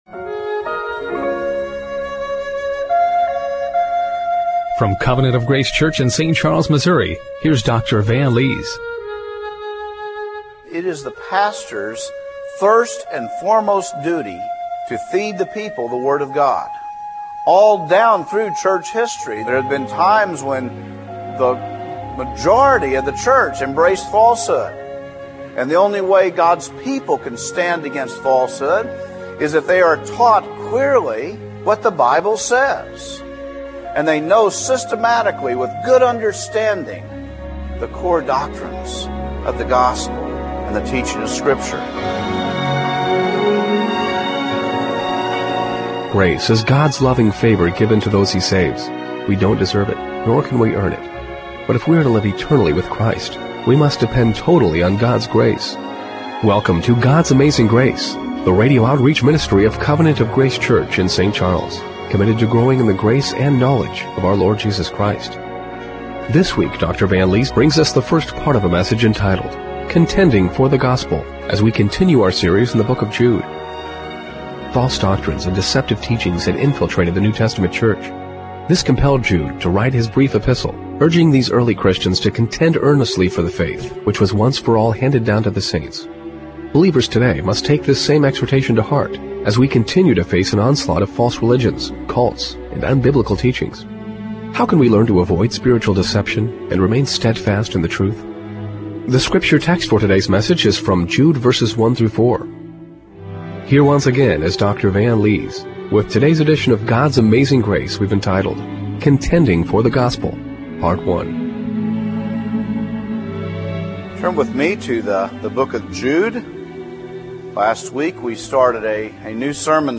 Jude 1:1-4 Service Type: Radio Broadcast How can we learn to avoid spiritual deception and remain steadfast in the truth?